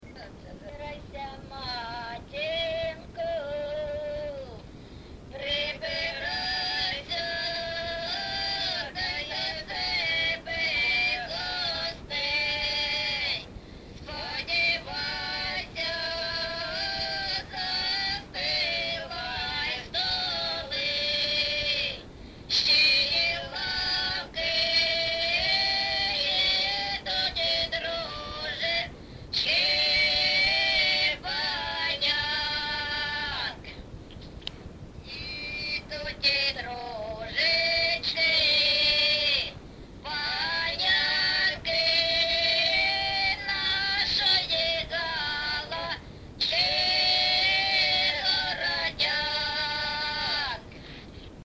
ЖанрВесільні
Місце записус. Некременне, Олександрівський (Краматорський) район, Донецька обл., Україна, Слобожанщина